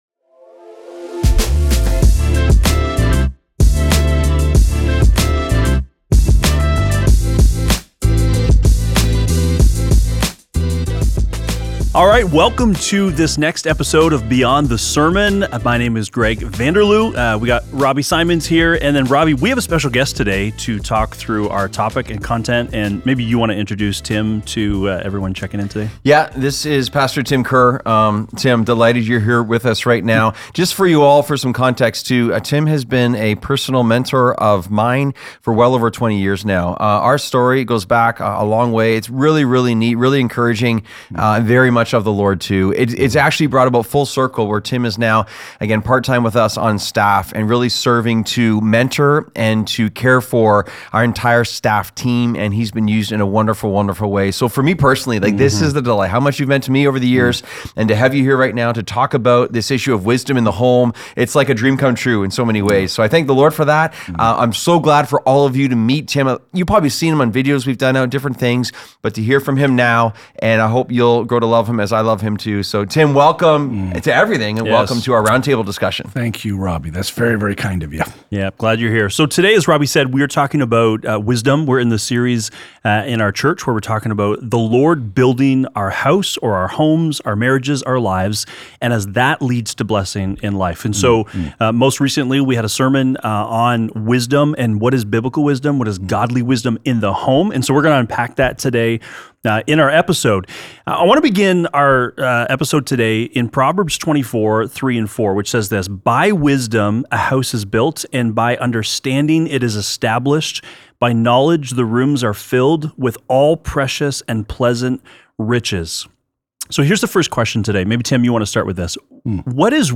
Conversations on Wisdom in the Home